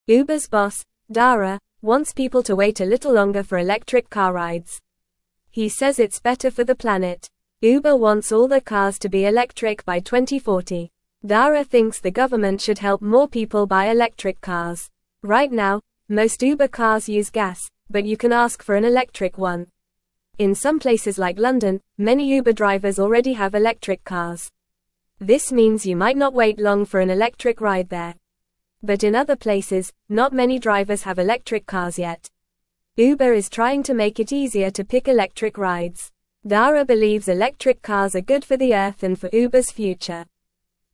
Fast
English-Newsroom-Beginner-FAST-Reading-Uber-Boss-Wants-More-Electric-Cars-for-Rides.mp3